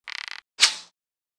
Index of /App/sound/monster/barbarian_bow
attack_act_1.wav